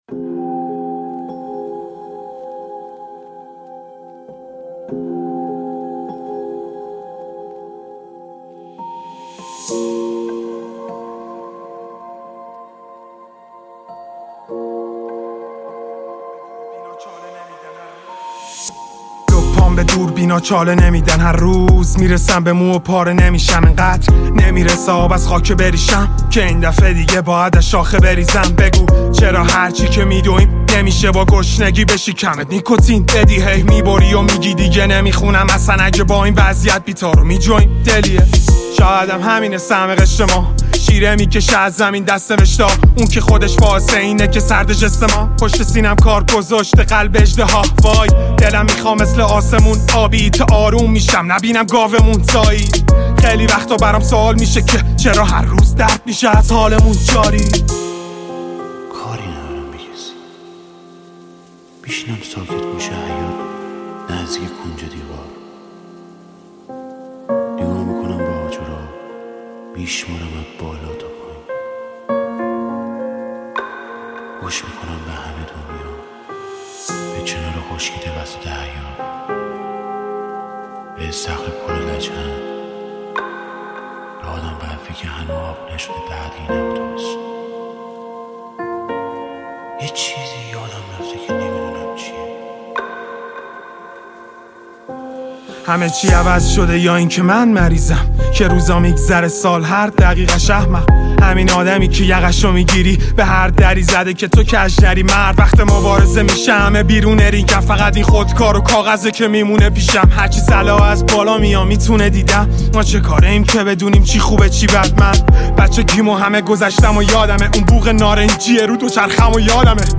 گنگ رپ